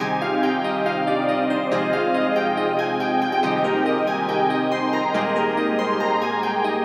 标签： 杂项 卡通 动画 配乐 OST 可爱 快乐 背景 音乐 悲伤 四弦琴
声道立体声